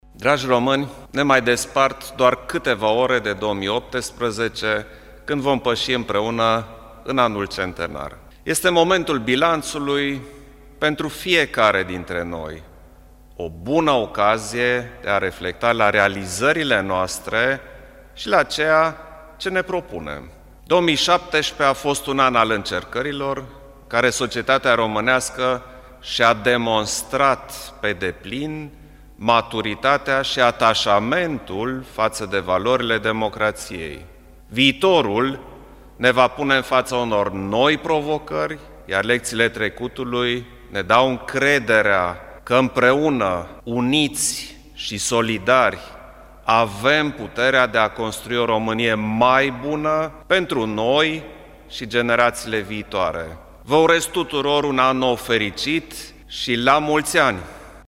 Preşedintele Klaus Iohannis a transmis românilor un mesaj de felicitare cu ocazia Anului Nou, în care spune că 2017 a fost un an al încercărilor, în care societatea şi-a demonstrat maturitatea şi ataşamentul faţă de valorile democraţiei, iar lecţiile trecutului ne dau încrederea că uniţi şi solidari avem puterea de a construi o Românie mai bună: